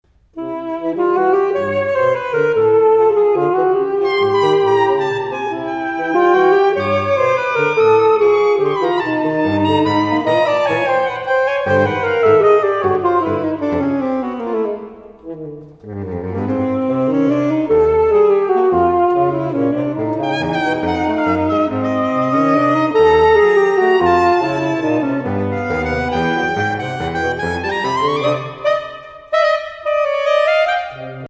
Obsazení: 4 Saxophone (SATBar)
těžká 5-sätzige Suite.